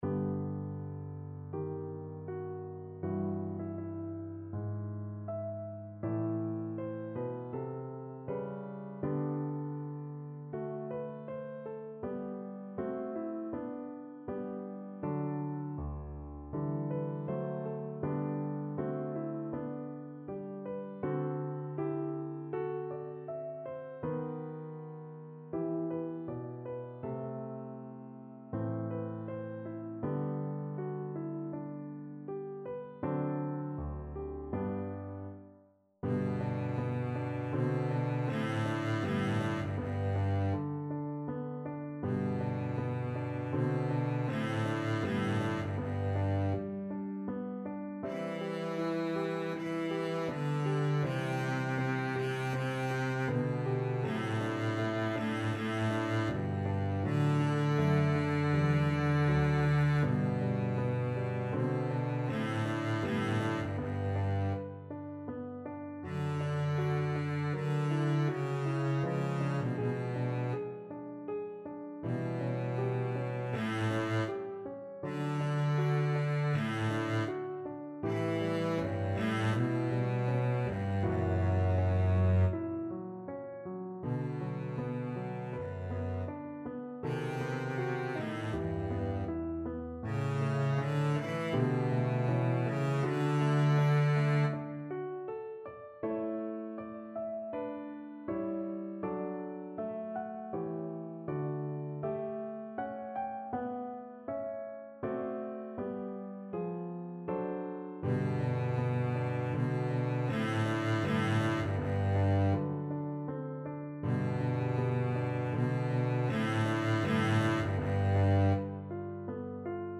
Double Bass
4/4 (View more 4/4 Music)
Poco lento =80
G major (Sounding Pitch) (View more G major Music for Double Bass )
Classical (View more Classical Double Bass Music)